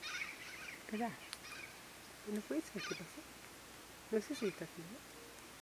Nome científico: Psittacara mitratus
Nome em Inglês: Mitred Parakeet
Localidade ou área protegida: Reserva Natural Privada Ecoportal de Piedra
Condição: Selvagem
Certeza: Gravado Vocal
calancate-cara-roja.mp3